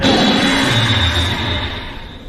Guncaeser_live_roar.ogg